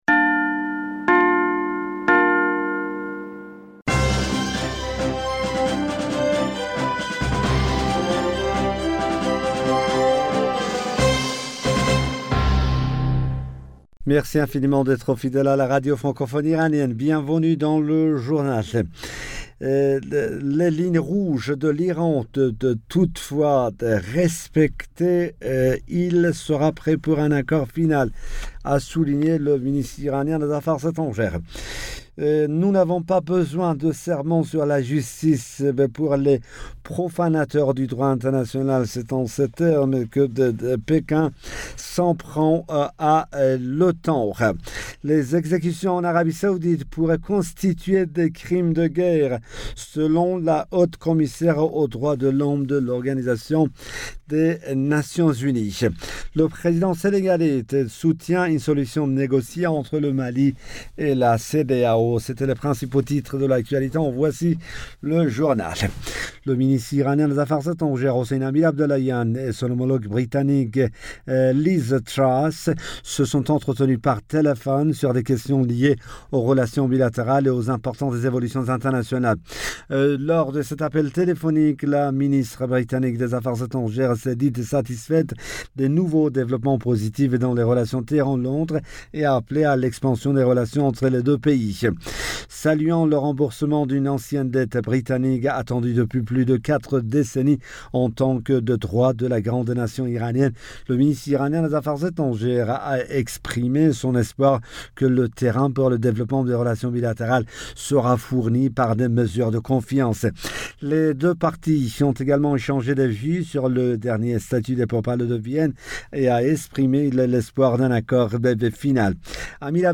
Bulletin d'information Du 18 Mars 2022